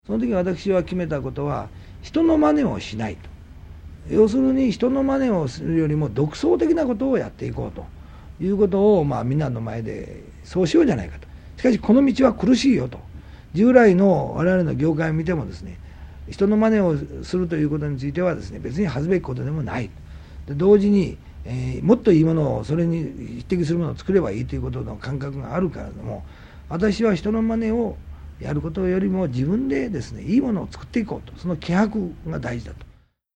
会社再建を託されたトップとして逆境をどう乗り越えたのか、その舞台裏を本人が語ります。
（NHKラジオ第1「人生読本」1989年5月15〜17日放送より）